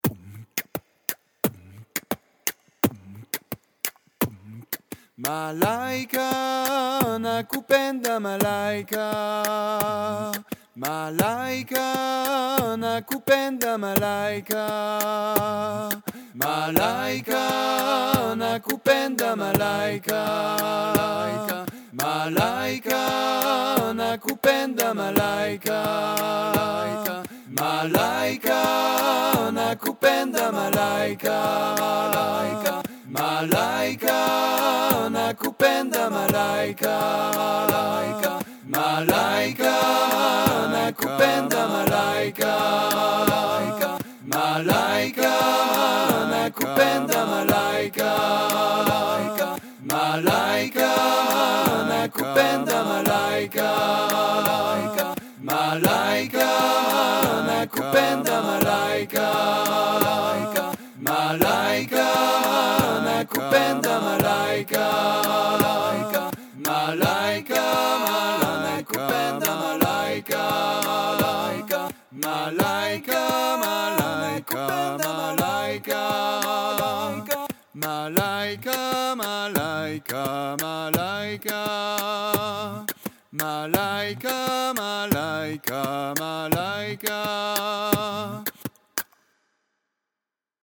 Le Beat Box et les 4 voix ensemble :